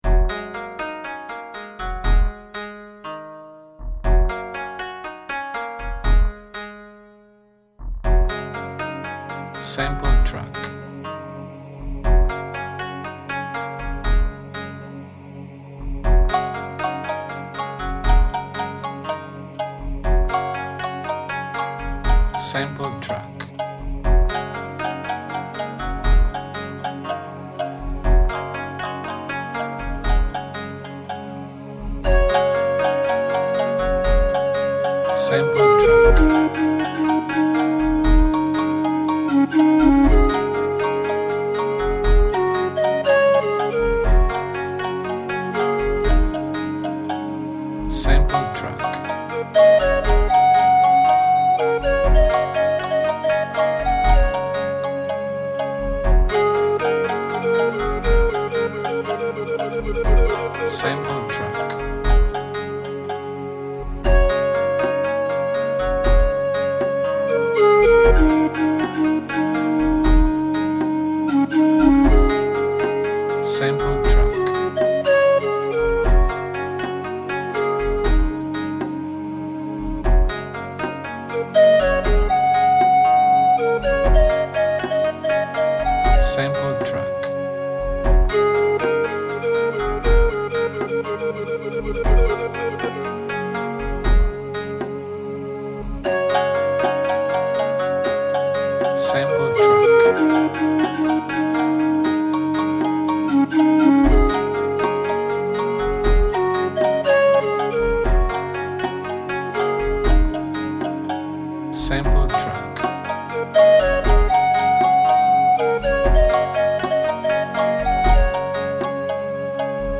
GIAPPONE Strumenti e melodie tipici del Giappone